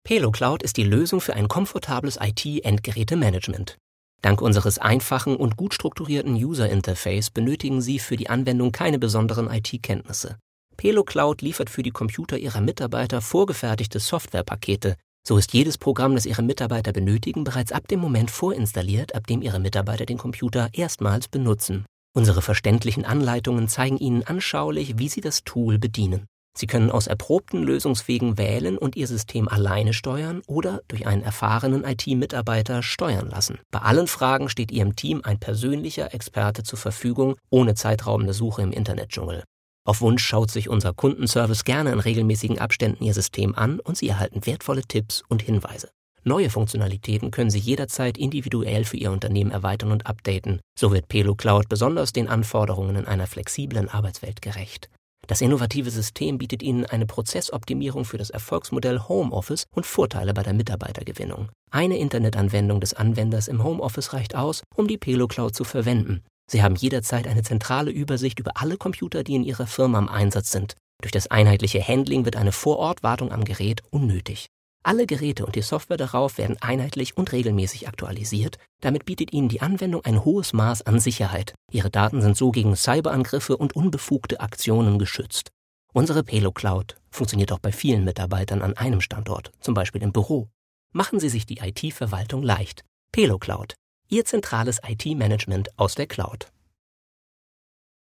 Stimmfarbe jung, dynamisch, natürlich, kernig, zart.
norddeutsch
Sprechprobe: Industrie (Muttersprache):
My voice sounds young, dynamic, naturalistic, tender.